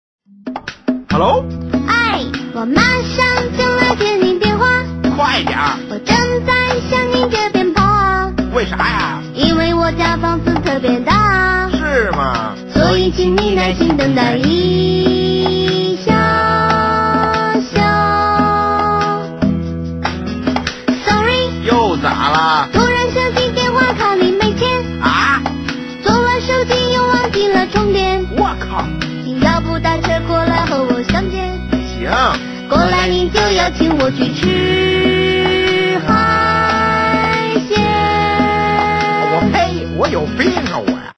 短信铃声